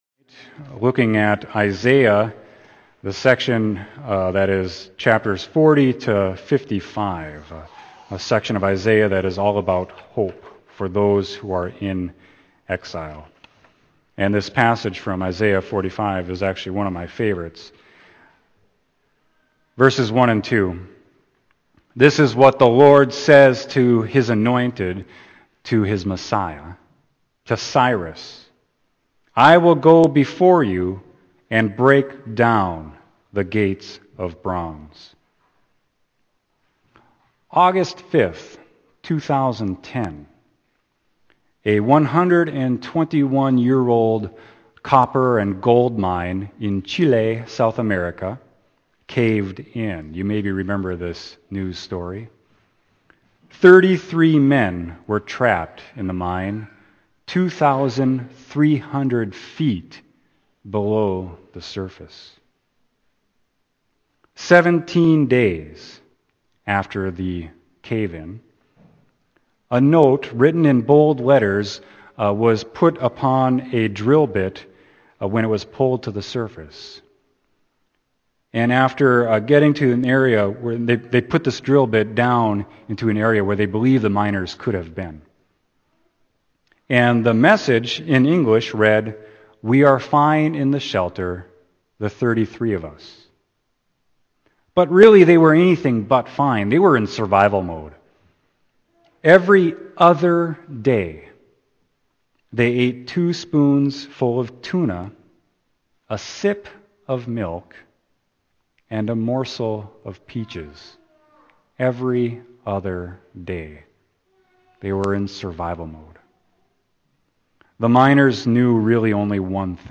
Sermon: Isaiah 45.1-8